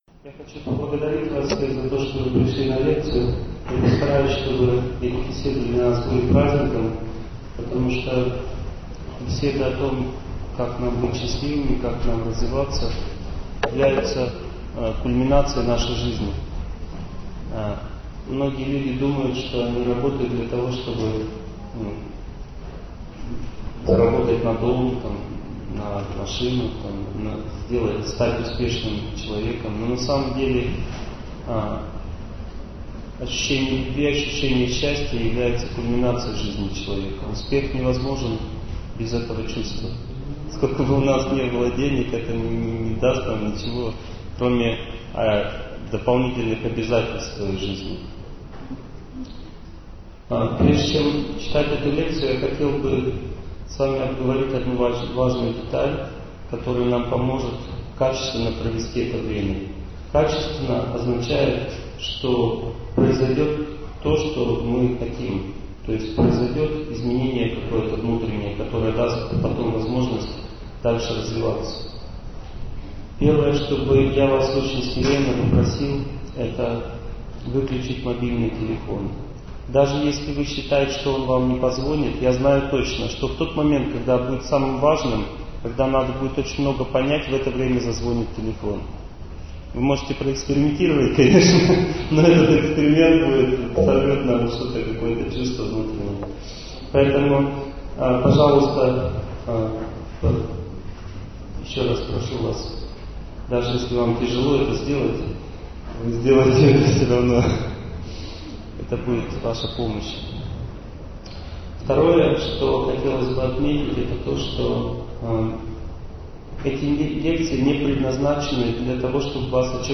Aудиокнига Развитие разума